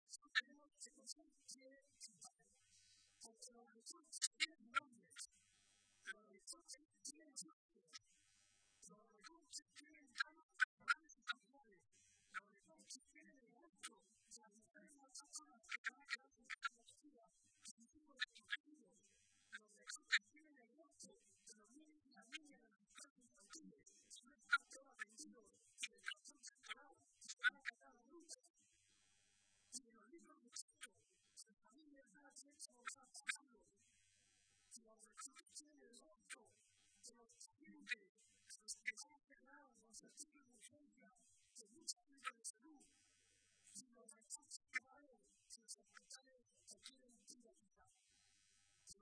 “Hemos trasformado más la sociedad que el propio partido y el partido que ha trasformado la sociedad ahora se tiene también que trasformar para estar a la altura de esa nueva sociedad”, señaló Barreda durante su intervención en la tradicional comida navideña de los socialistas de la provincia de Toledo a la que asistieron más de 1.000 personas.
Intervención de Barreda